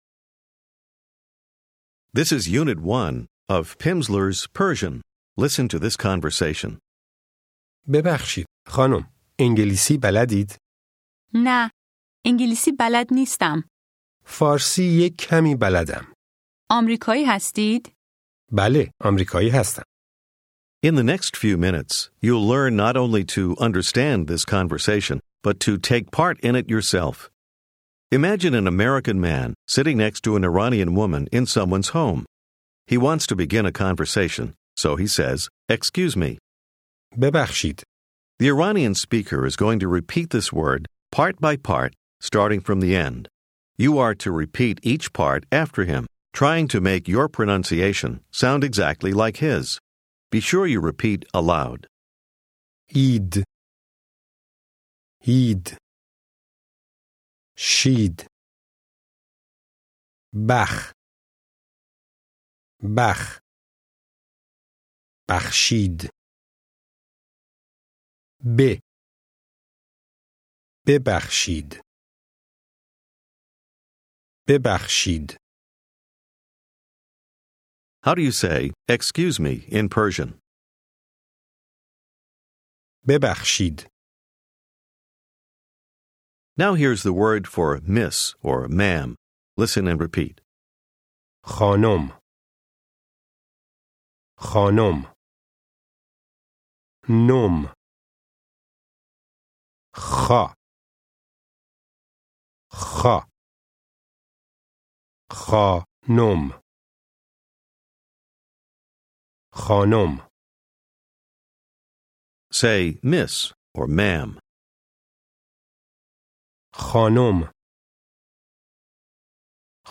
Farsi Persian Phase 1, Unit 1 contains 30 minutes of spoken language practice, with an introductory conversation, and isolated vocabulary and structures.